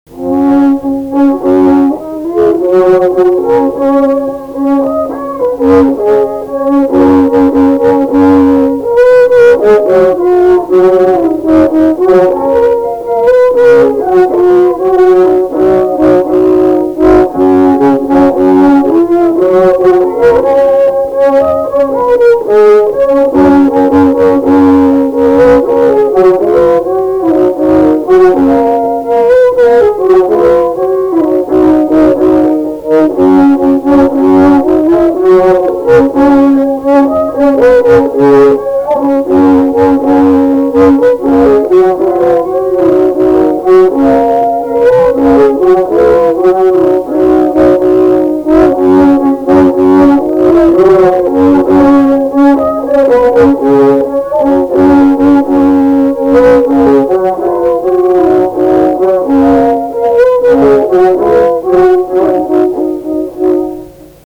šokis